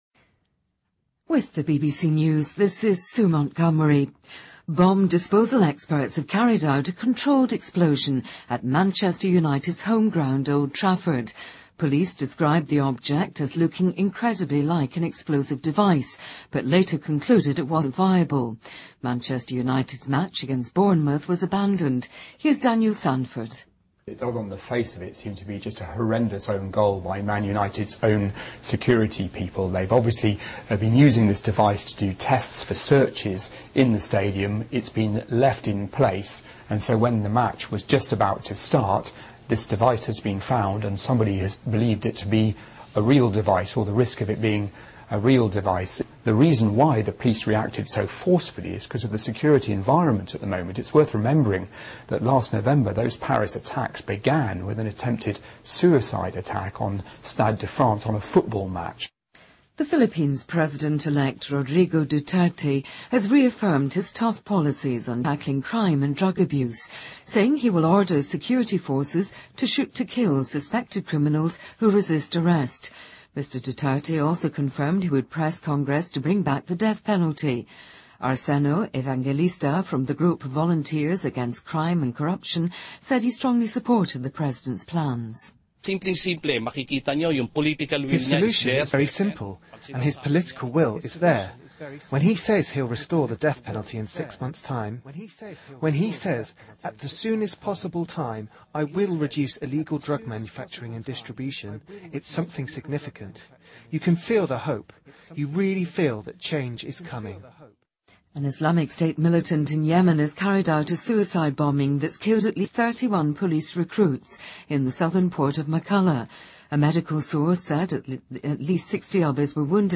BBC news,英超曼联主场老特拉福德惊现“爆炸物”